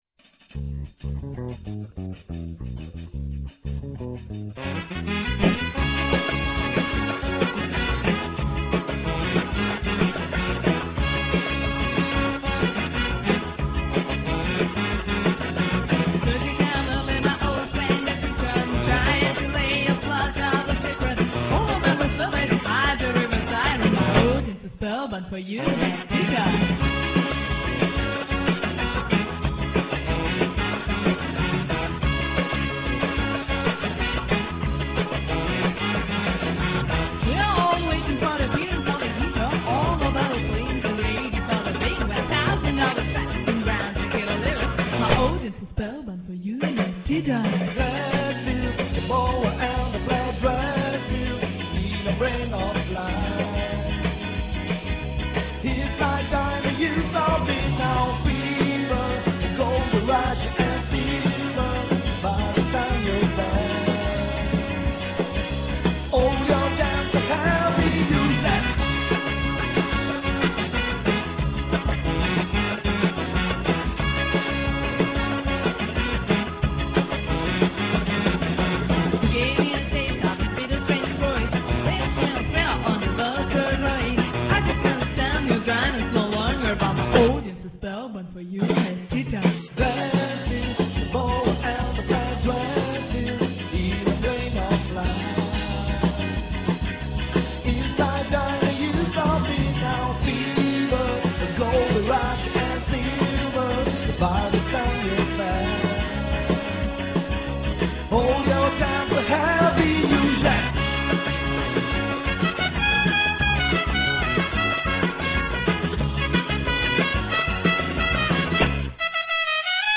Il battito in levare che si diffonde dall'Abruzzo...